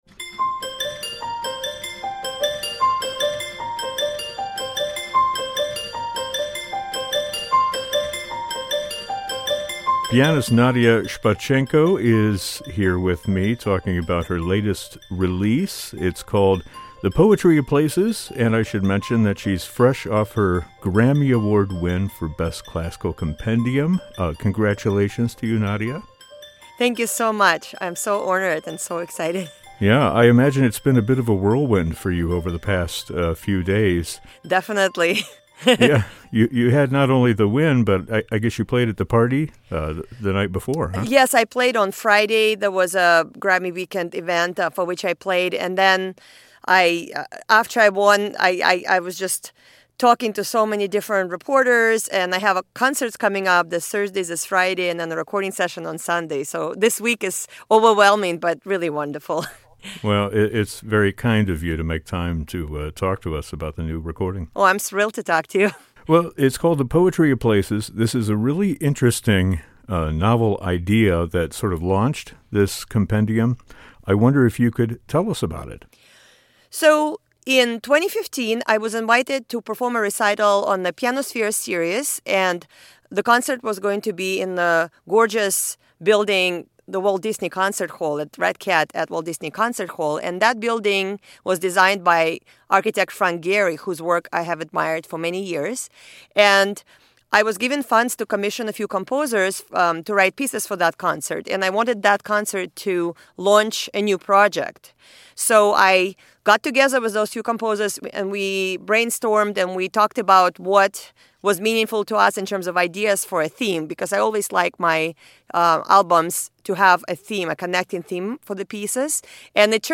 Featuring several world premieres, the disc has a fascinating premise: composers writing music inspired by the diverse architectural landmarks that have played a role in their lives. In addition to the piano, there are works that include percussion, electronics, voice, and toy piano